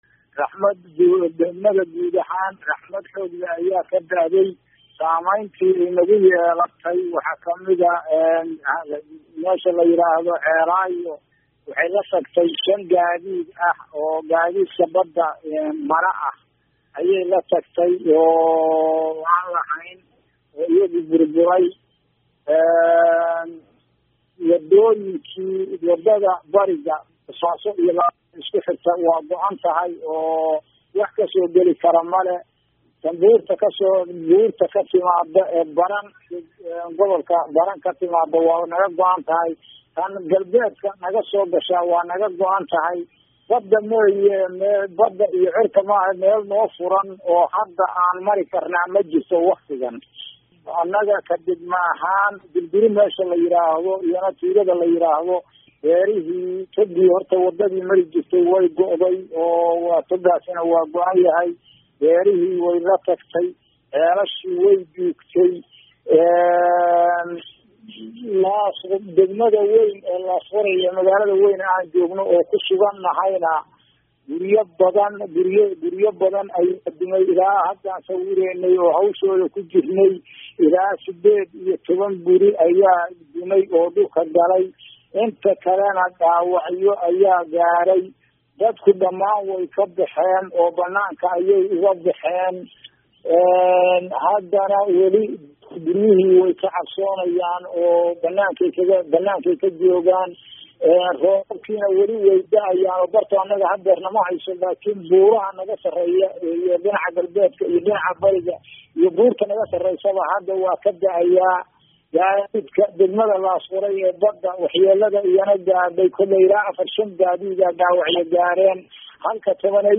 Wareysi Gud. Ku-xigeenka Laasqoray